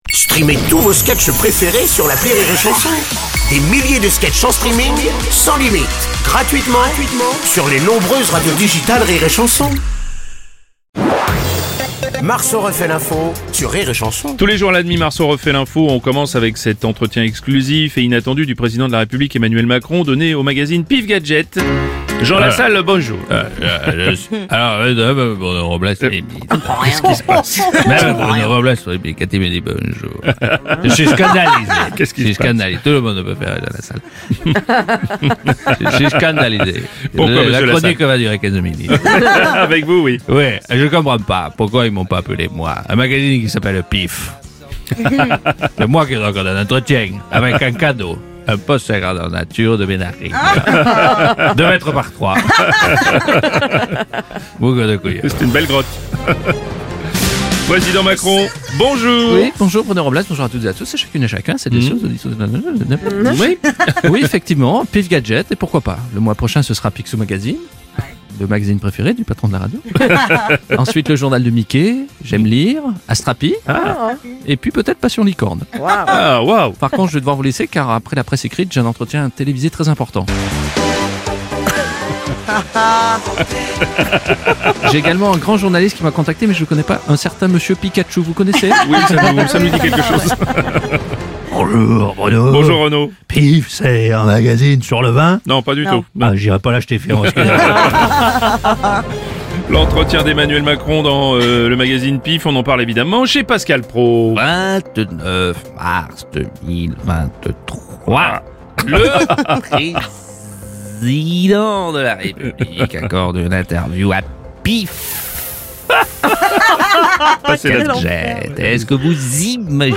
Interview jeudi - Semaine spéciale Les Duos Impossibles de Jérémy Ferrari n°9 - Avec Jérémy Ferrari, Baptiste Lecaplain et Arnaud Tsamère - 30.03.2023